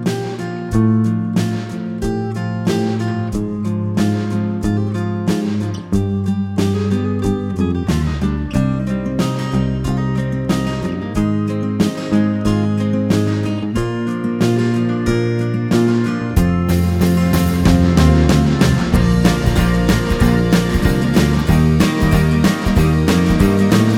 No Harmony Pop (1960s) 4:40 Buy £1.50